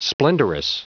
Prononciation du mot splendrous en anglais (fichier audio)
Prononciation du mot : splendrous